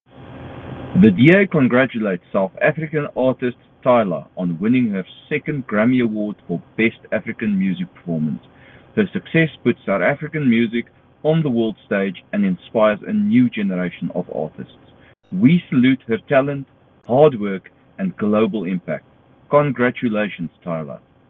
soundbite by Jan de Villiers MP.